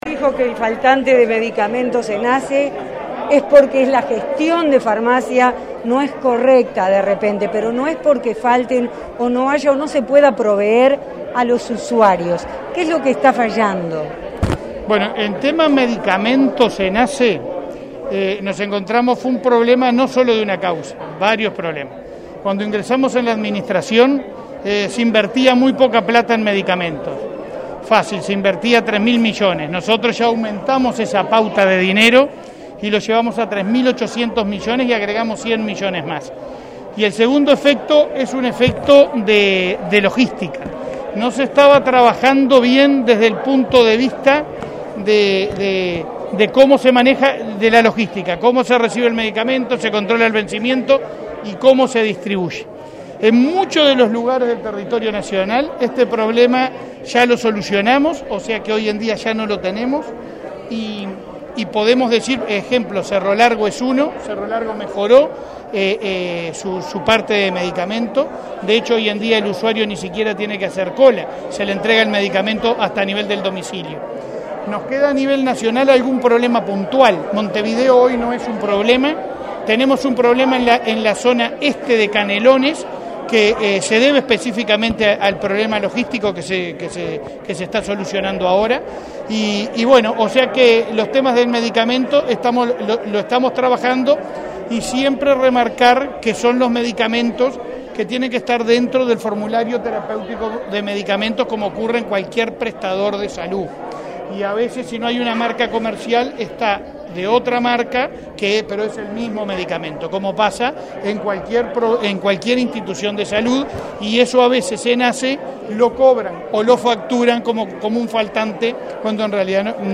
Declaraciones a la prensa del presidente de ASSE, Leonardo Cipriani
Declaraciones a la prensa del presidente de ASSE, Leonardo Cipriani 13/12/2021 Compartir Facebook X Copiar enlace WhatsApp LinkedIn Tras participar en la inauguración de obras en el hospital de Melo, este 13 de diciembre, el presidente de ASSE, Leonardo Cipriani, efectuó declaraciones a la prensa.